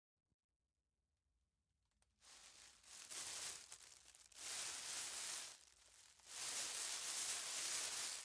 塑料袋
描述：塑料沙沙作响